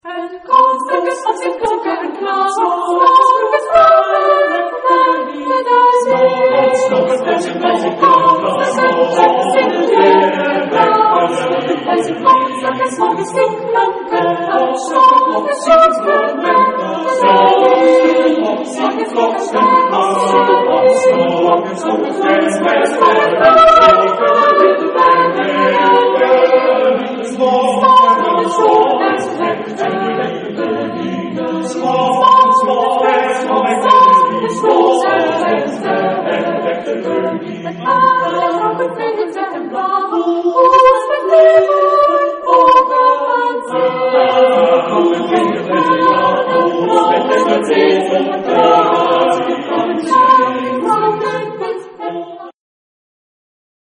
Genre-Stil-Form: Liedsatz ; Renaissance ; weltlich
Chorgattung: SATB  (4 gemischter Chor Stimmen )
Tonart(en): F-Dur